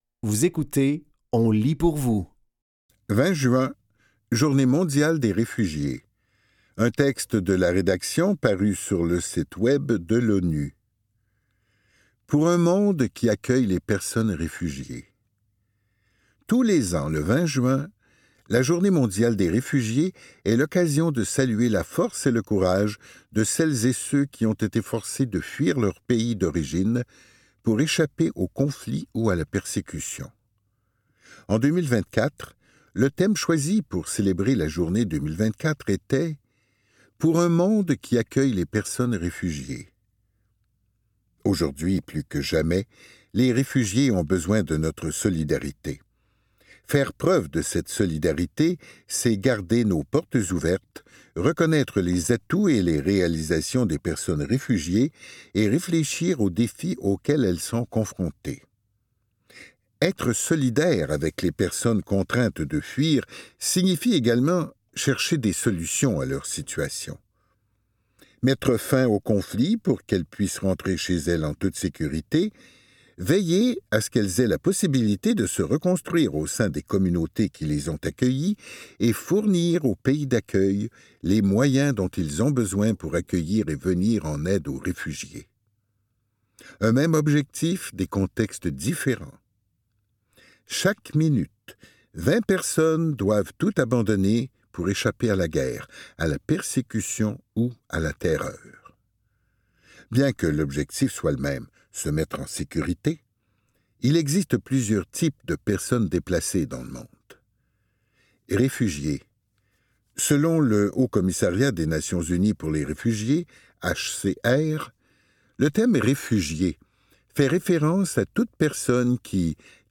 Dans cet épisode de On lit pour vous, nous vous offrons une sélection de textes tirés des médias suivants : l'ONU, Le Devoir et Les Libraires. Au programme: 20 juin – Journée mondiale des réfugiés, un texte de la rédaction, paru sur le site web de l'ONU.(0:02) Revenir au respect par le «vous» est un…